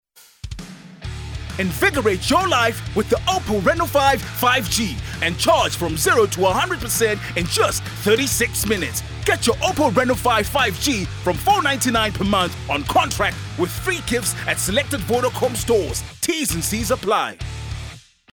South Africa
clear, crisp, precise, sharp
My demo reels